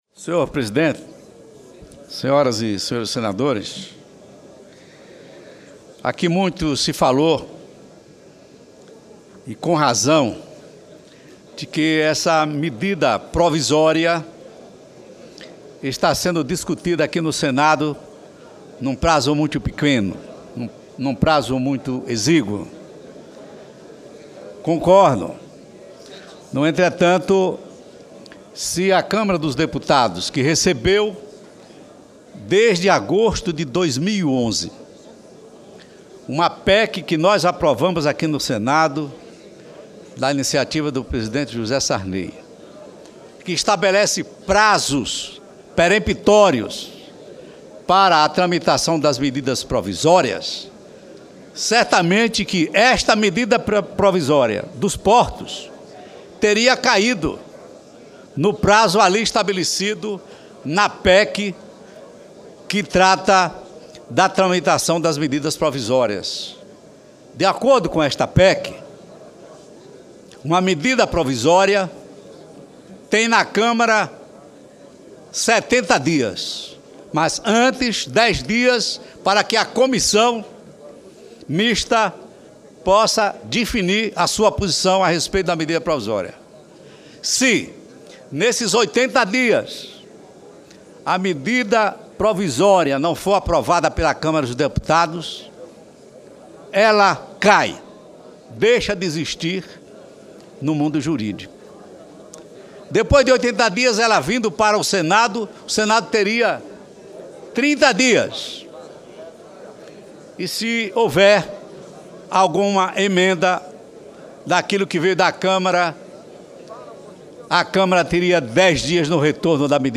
Pronunciamento do senador Antonio Carlos Valadares